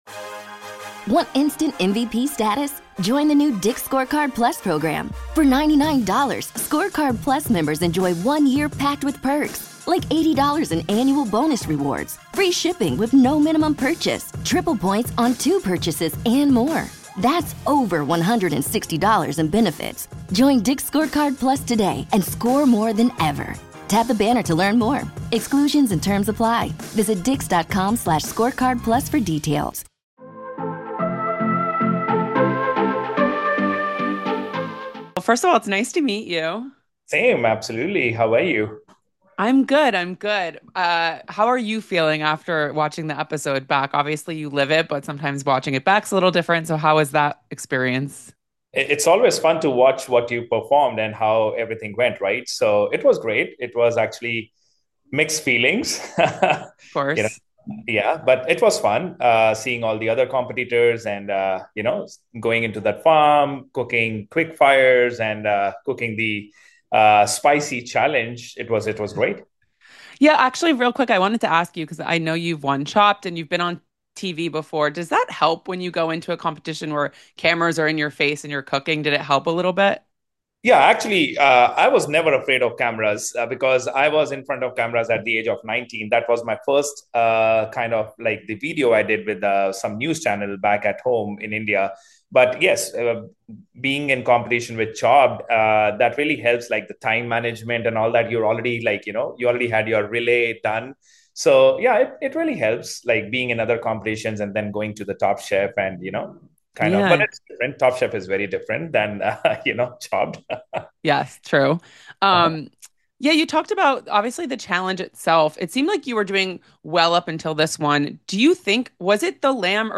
Exit Interview